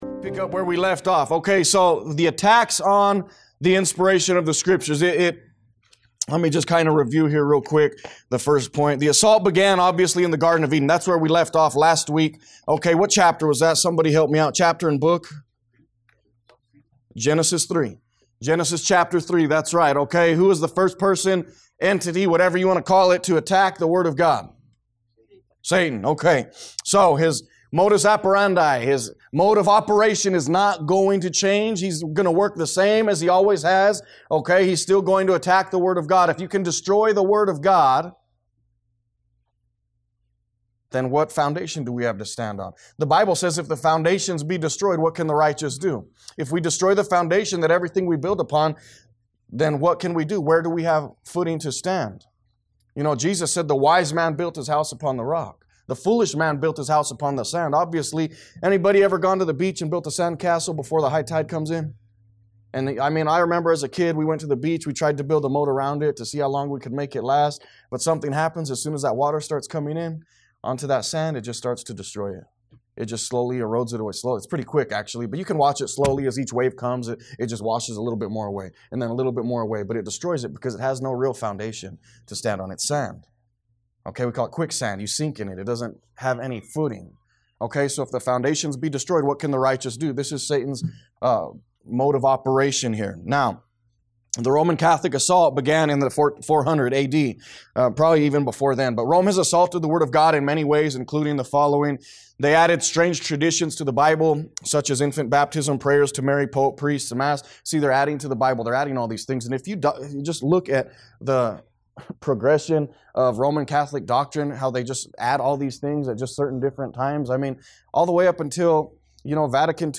A message from the series "Doctrine of The Bible."